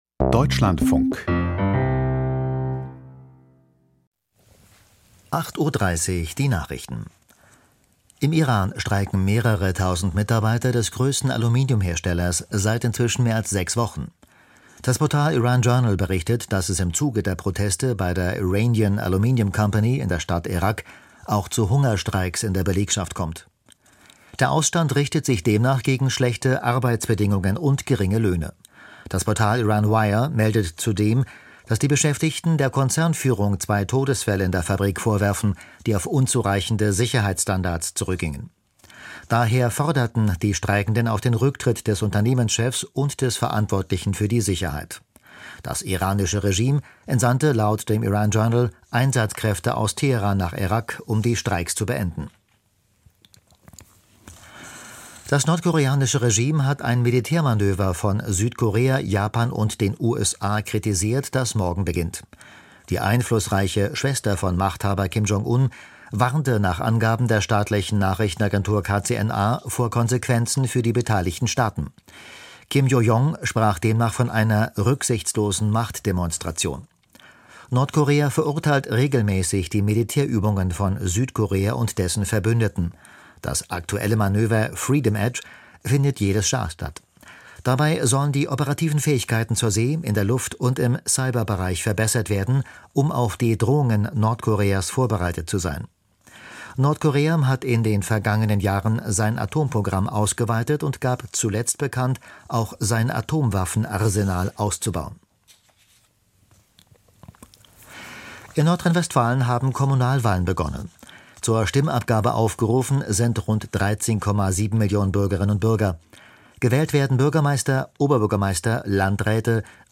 Die Nachrichten vom 14.09.2025, 08:30 Uhr
Aus der Deutschlandfunk-Nachrichtenredaktion.